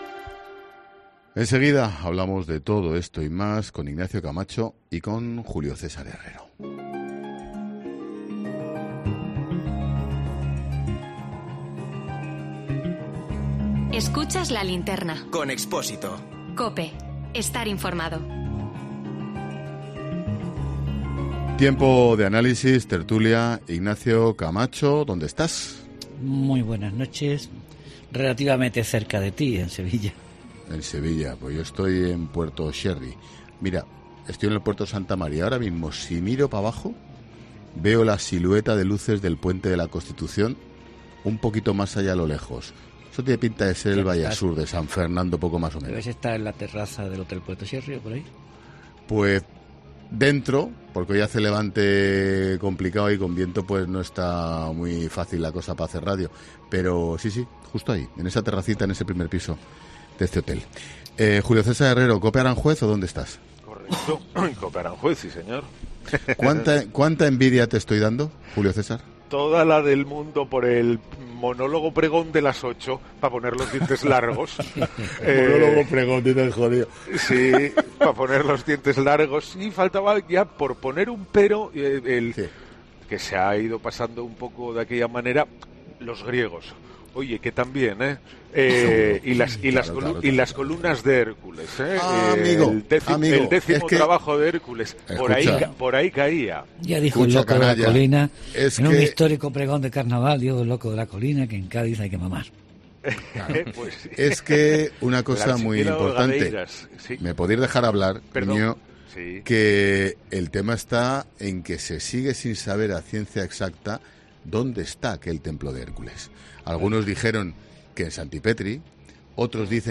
Germán Beardo en La Linterna con Ángel Expósito desde Puerto Sherry